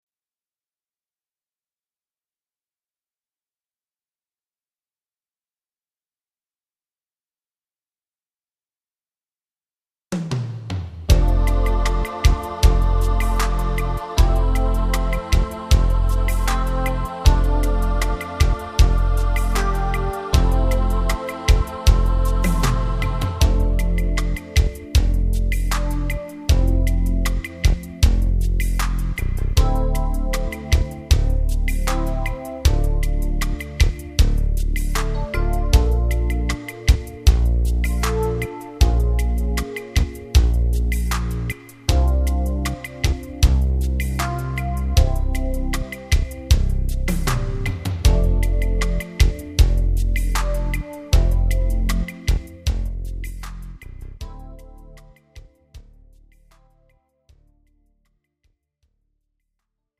(팝송) MR 반주입니다.